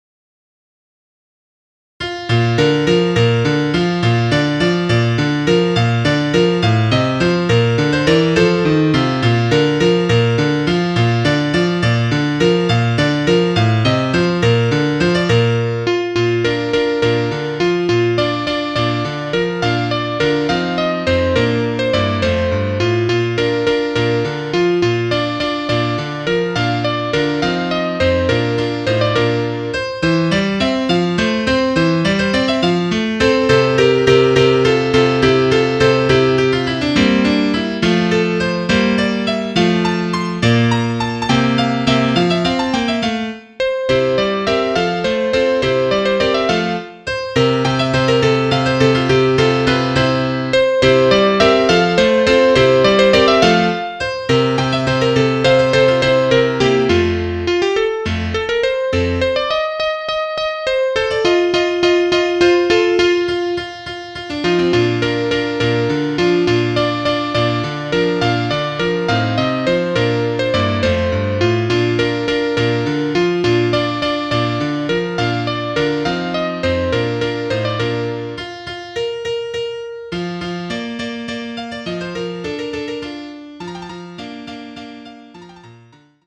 Bearbeitung in  B - Dur
für ein Soloinstrument mit Klavierbegleitung